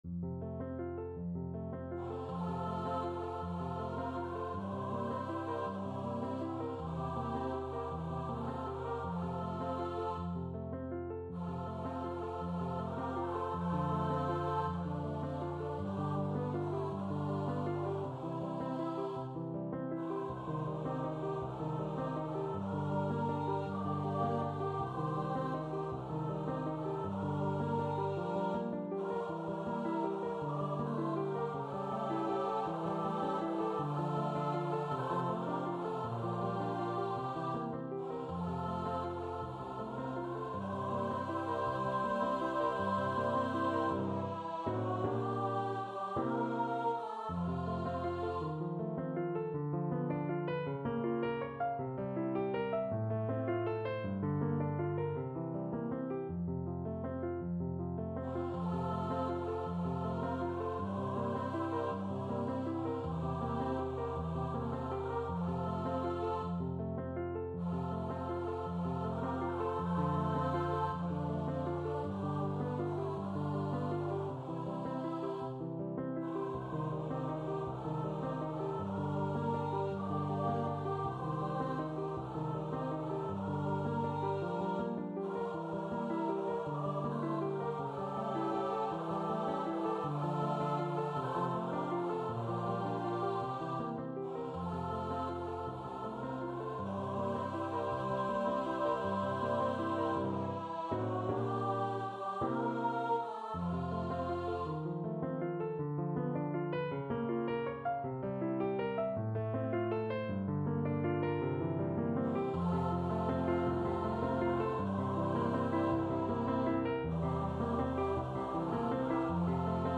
Free Sheet music for Choir (SAB)
SopranoAltoBass
6/8 (View more 6/8 Music)
Andante tranquillo
Classical (View more Classical Choir Music)